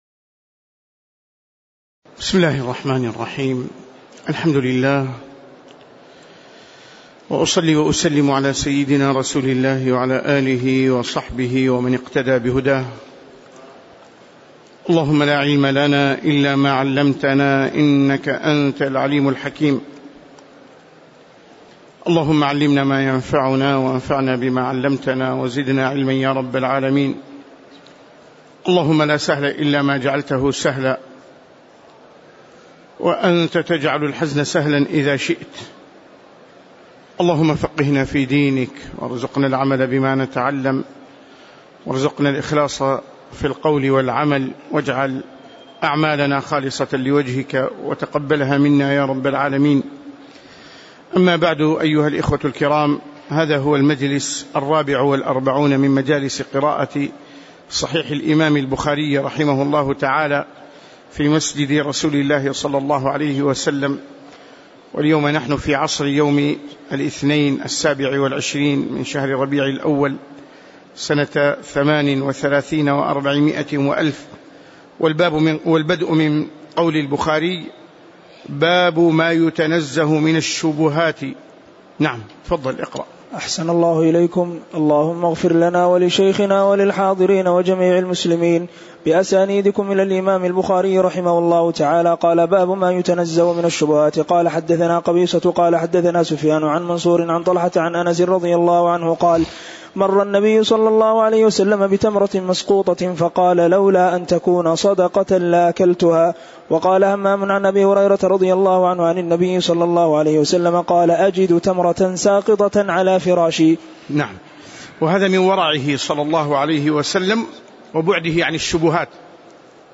تاريخ النشر ٢٧ ربيع الأول ١٤٣٨ هـ المكان: المسجد النبوي الشيخ